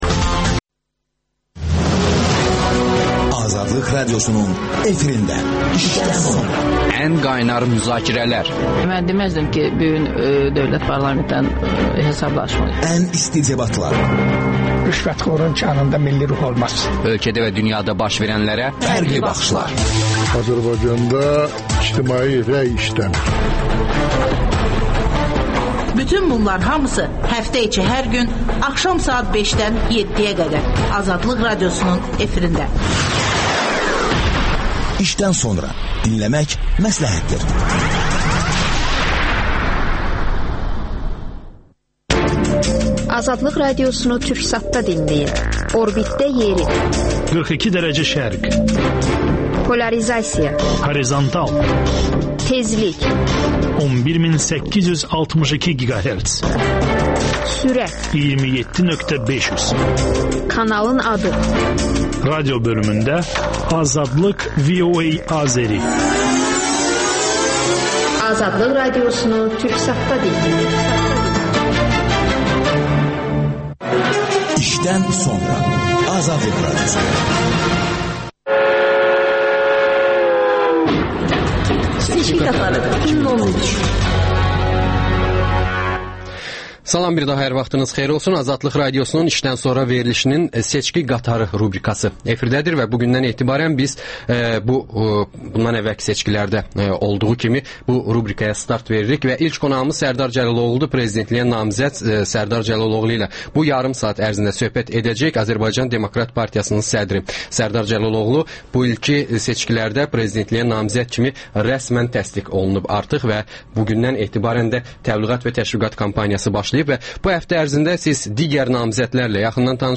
Azərbaycan Demokrat Partiyasının sədri dinləyicilərin suallarına cavab verir.